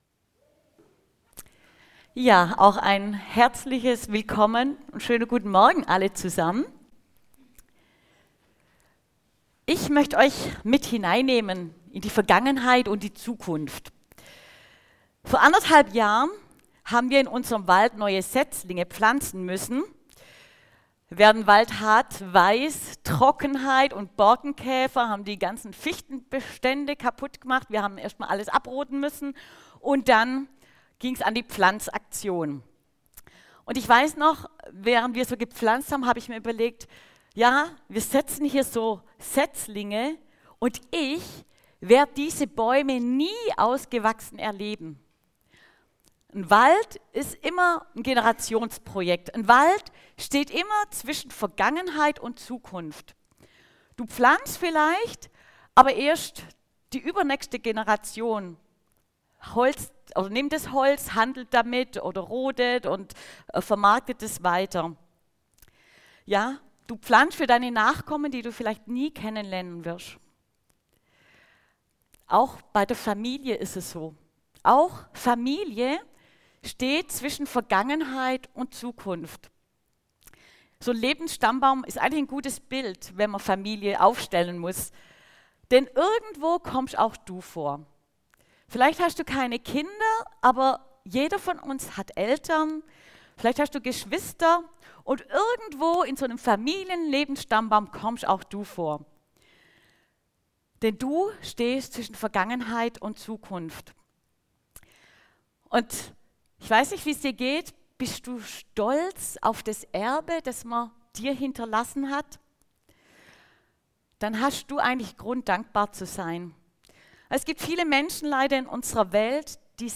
Josia - Zwischen Vergangenheit und Zukunft ~ Predigten - Gottesdienst mal anders Podcast